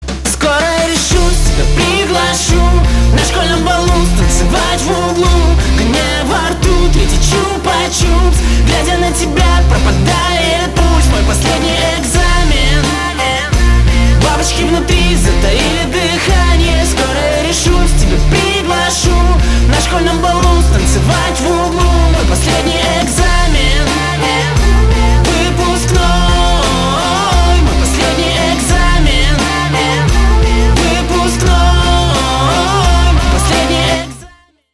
Рок Металл
грустные